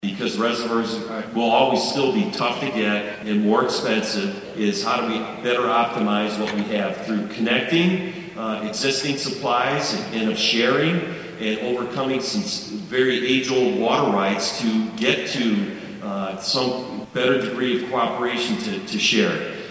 spoke to a gathering at Tuesday’s conference in Manhattan